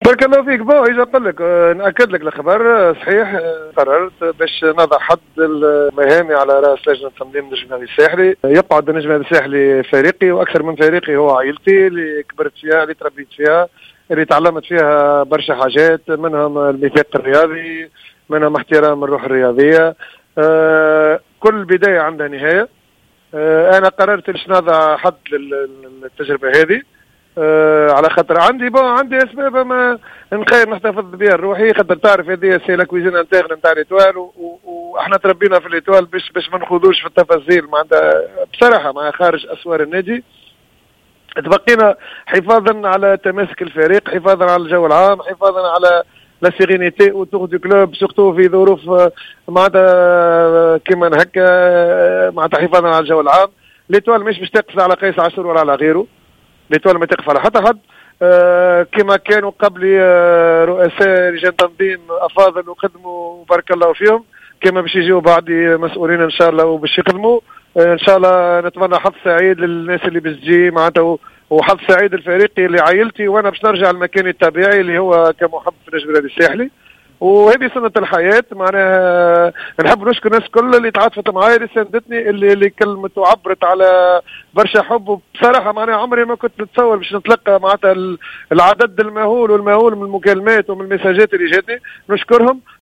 Dans une déclaration de presse accordée à Jawhara FM